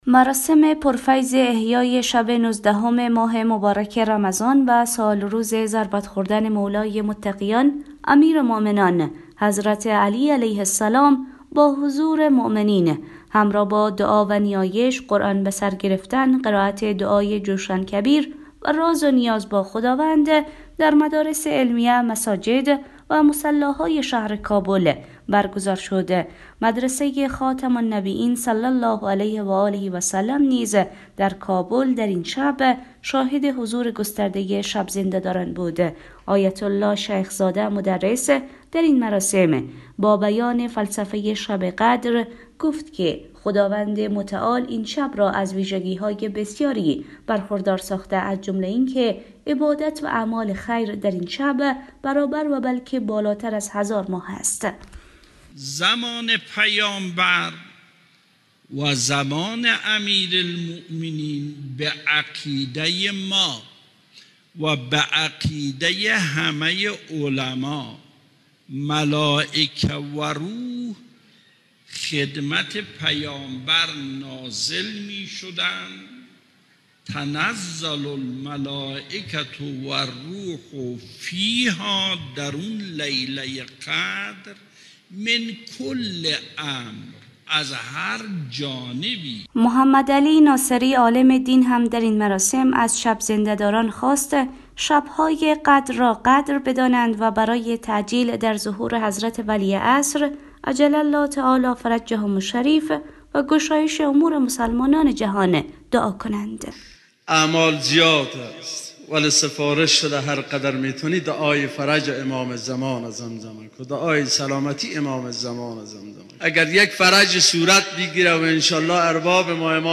مراسم پرفیض احیای شب نوزدهم ماه مبارک رمضان و سالروز ضربت خوردن مولای متقیان امیرمومنان حضرت علی (ع) با حضور مؤمنین همراه با دعا و نیایش، قرآن بر سر گرفتن...
گزارش